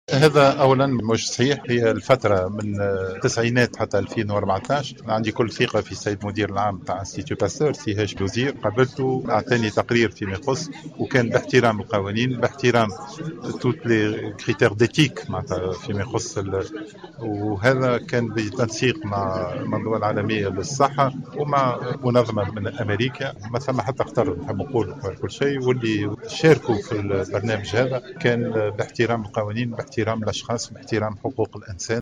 أكد وزير الصحة سعيد العايدي، في تصريح لمراسل الجوهرة أف أم، أنه لا صحة للاتهامات التي وجهت إلى معهد باستور، حول تورطه مع مؤسسة أمريكية لاجراء تجارب على أطفال من الجنوب التونسي لفائدة مخبر أدوية اسرائيلي.